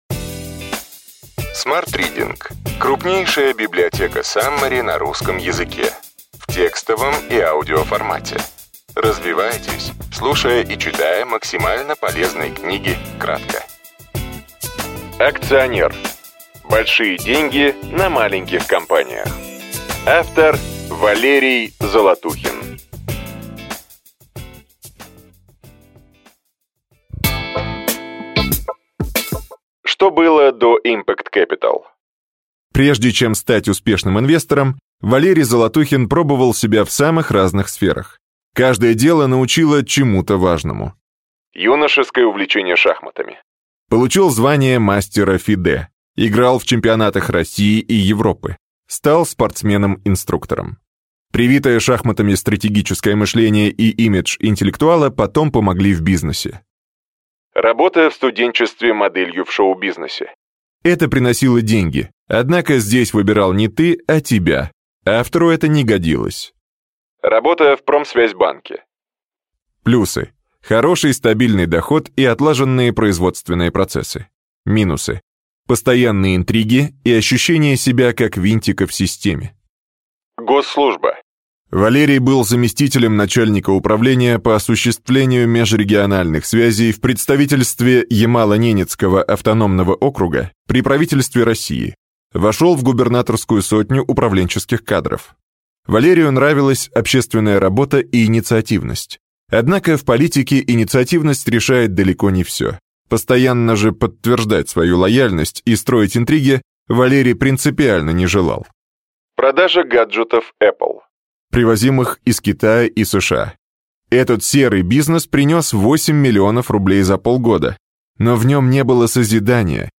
Аудиокнига Ключевые идеи книги: Акционер. Большие деньги на маленьких компаниях.